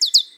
animalia_cardinal.3.ogg